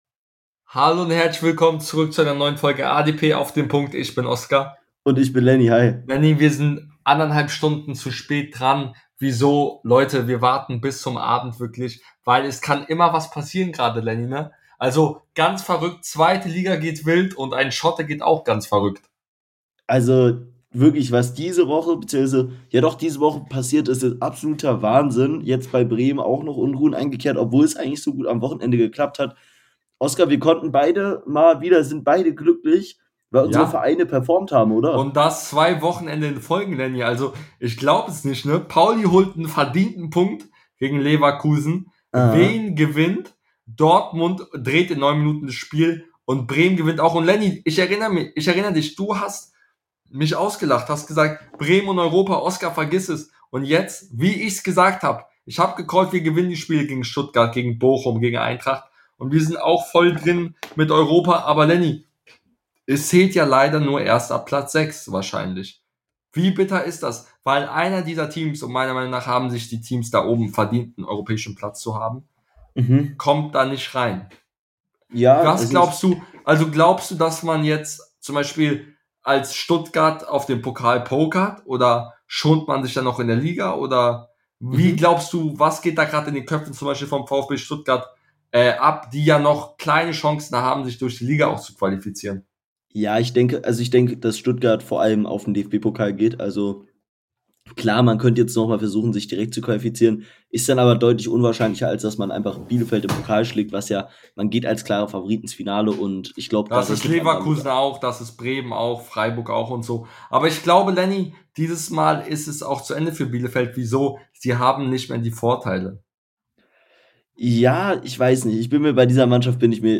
In der heutigen Folge reden die beiden Hosts über den Kampf um Europa , den Relegationsplatz und über den Aufstieg in Liga 1 . Ebenso sprechen sie über den Massentrainerrauschmiss in der 2 Liga und vieles mehr